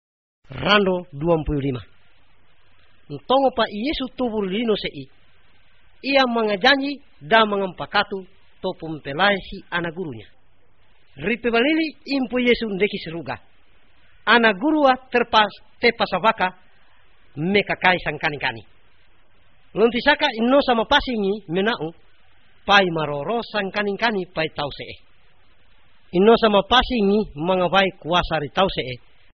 These are recorded by mother-tongue speakers
Bible Overview, Bible Stories, Discipleship